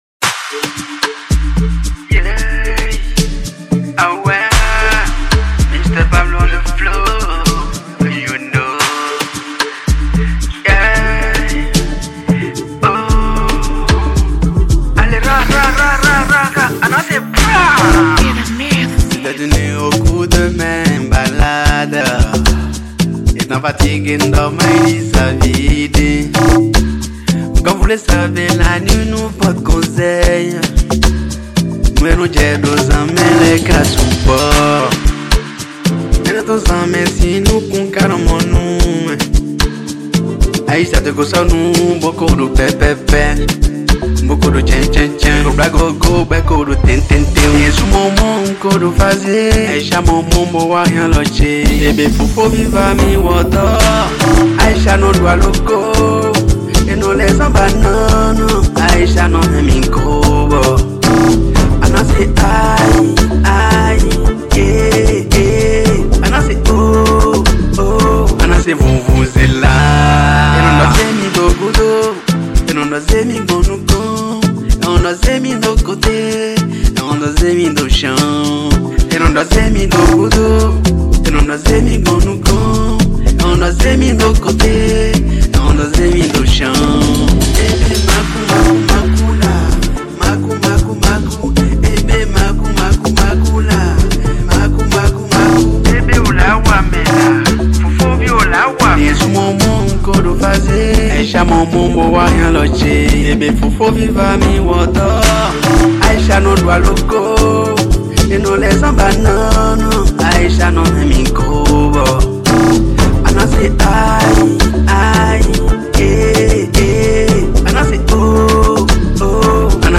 Catégorie : Zouk